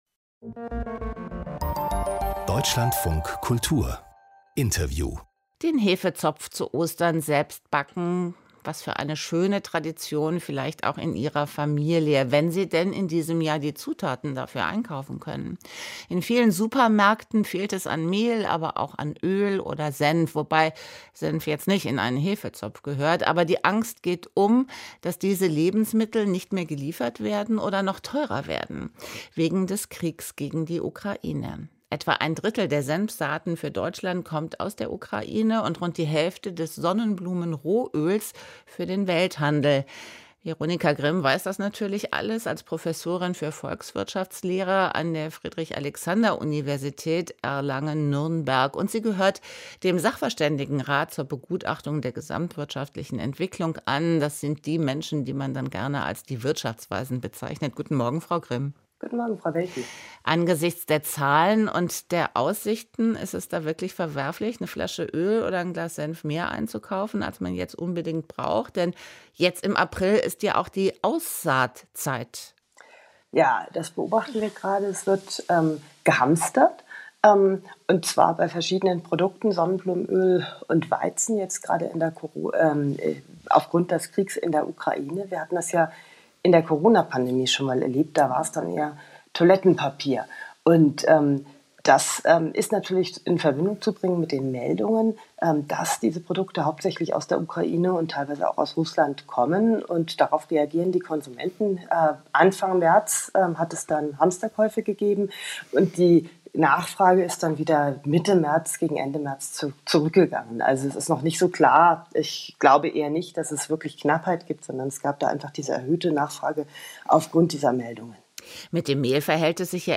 Veronika Grimm im Gespräch